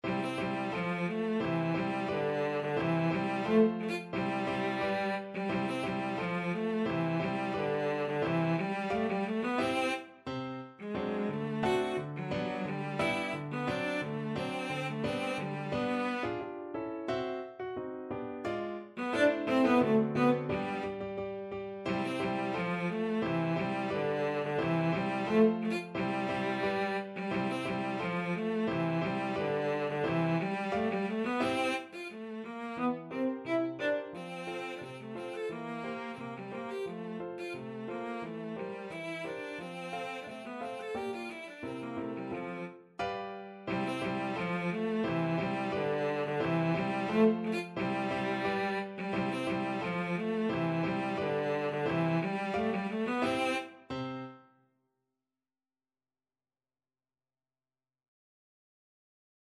Classical Strauss II,Johann Fledermaus-Quadrille, Op.363 Cello version
Cello
C major (Sounding Pitch) (View more C major Music for Cello )
2/4 (View more 2/4 Music)
~ = 88 Stately =c.88
Classical (View more Classical Cello Music)